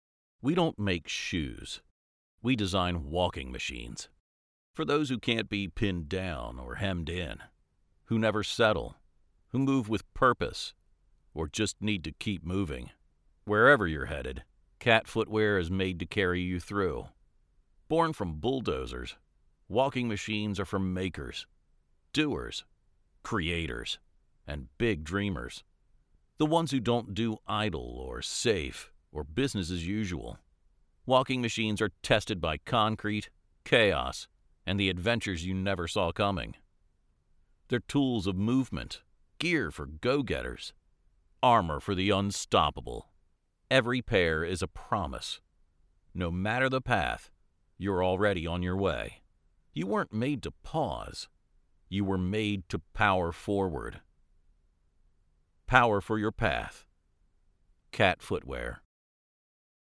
Male
Adult (30-50), Older Sound (50+)
Studio Quality Sample
Raw Audio Sample
0712Dry_Demo.mp3